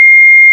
THERMIN.WAV